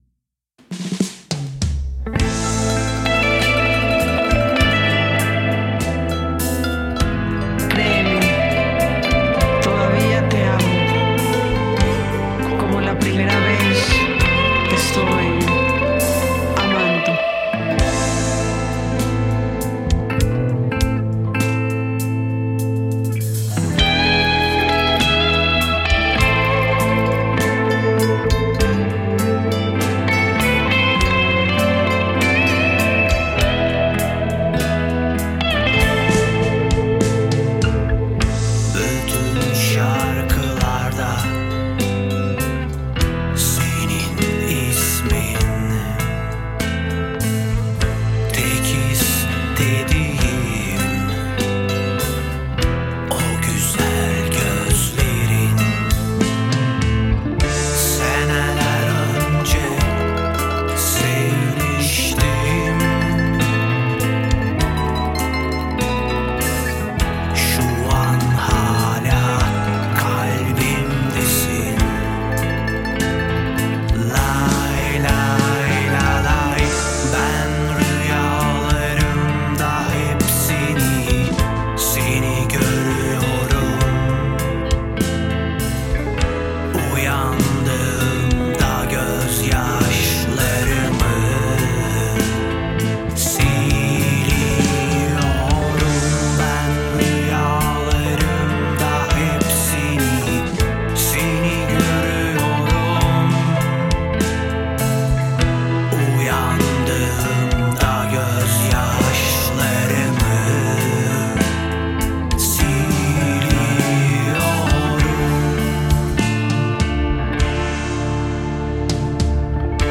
Mixture: Producción de un EP de fusión entre rock progresivo, gaita, flauta turca, acordeón, congas y mandolina